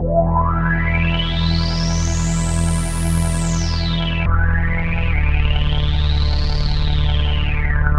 Synth 35.wav